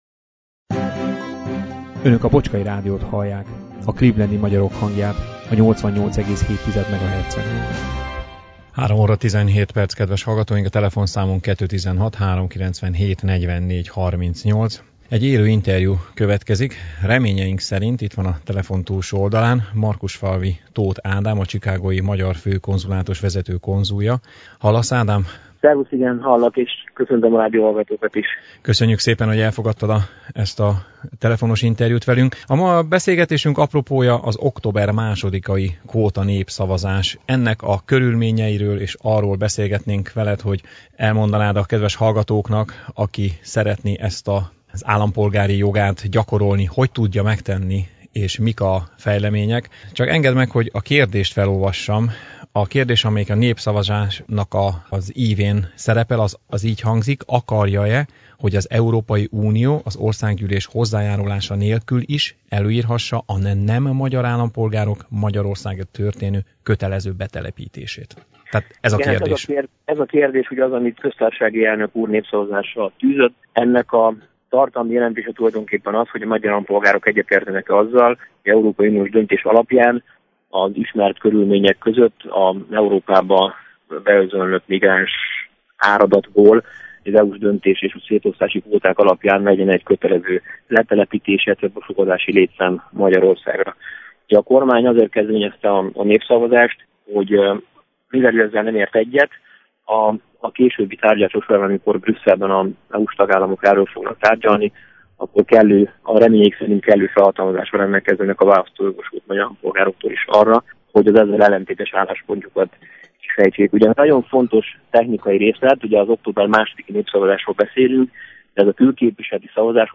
Fontosnak tartottuk mi is, hogy ezzel a témával foglalkozzunk, ezért hívtuk telefonon a vasárnapi élő adásunkba Márkusfalvi Tóth Ádámot  a Chicago-i Magyar Főkonzulátus vezető konzulját, hogy a legfontosabbakat ezen témakörben elmondja a hallgatóinknak.